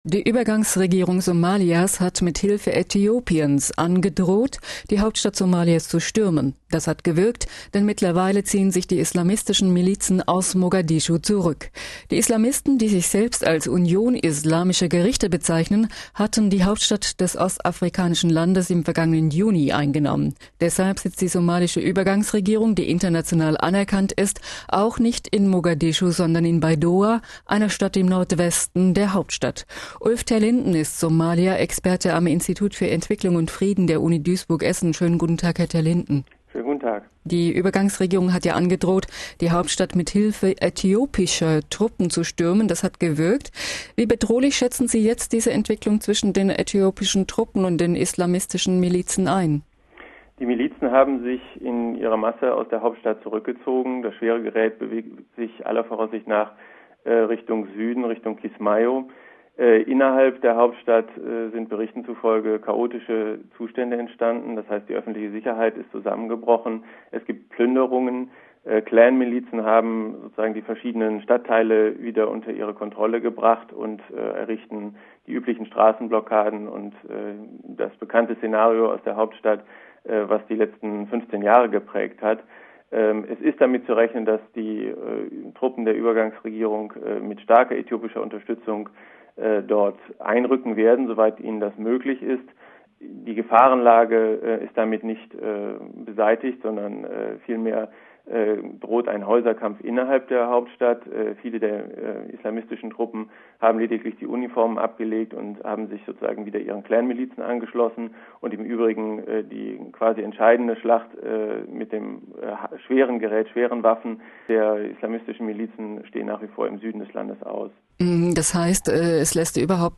Live Interview on the Crisis in Somalia: "Somalia: Islamisten ziehen sich zurück".